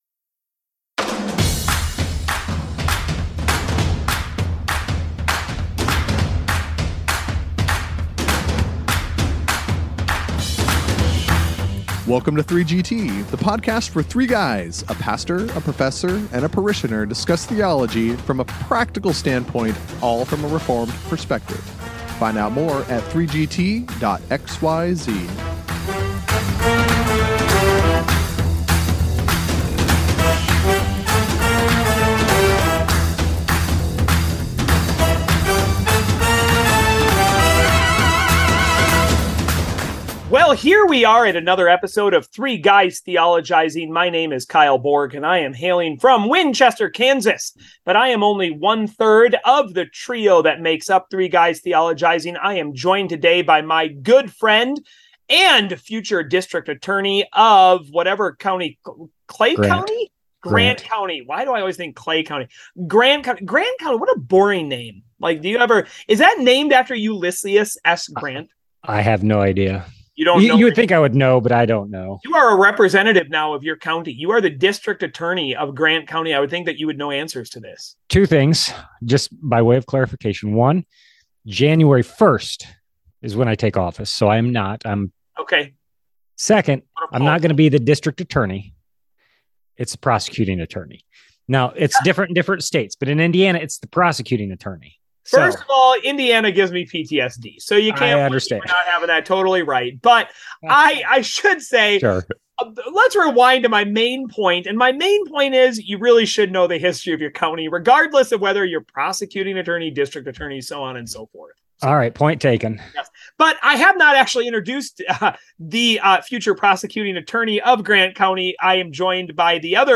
For the pastor and parishioner throw out some big words and then get down to business.